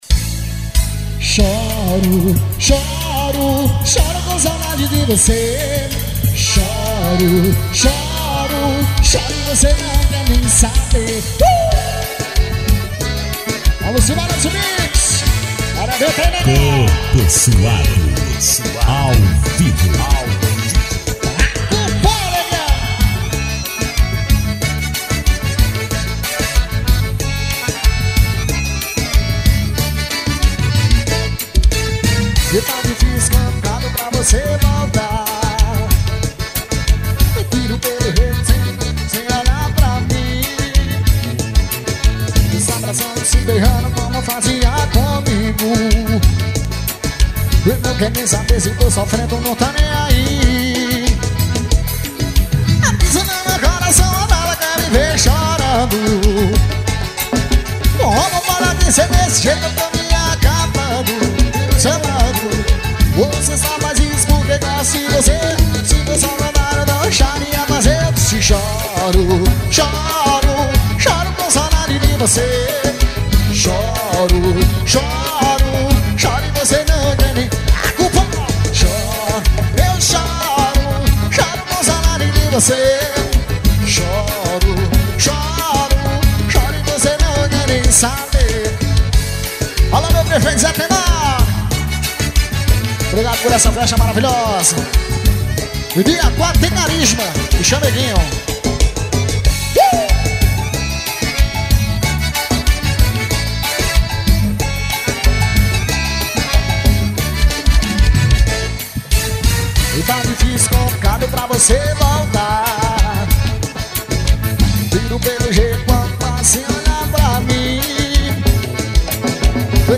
EstiloForró
ao vivo